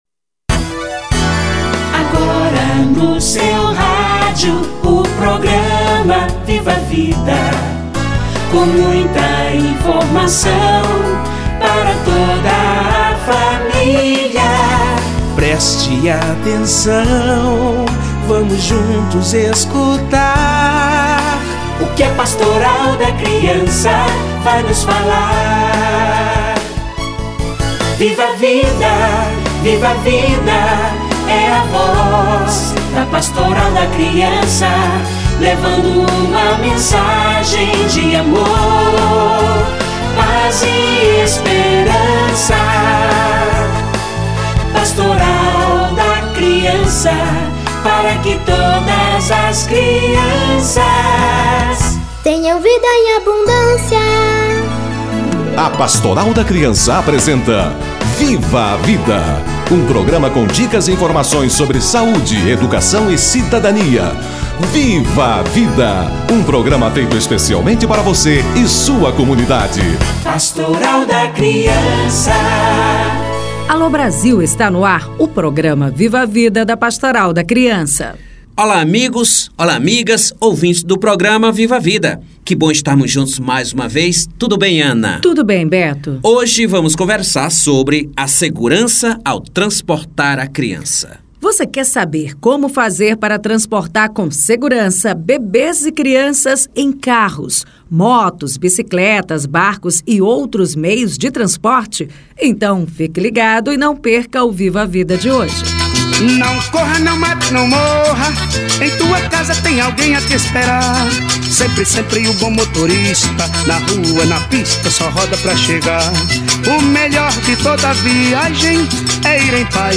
Segurança ao transportar a criança - Entrevista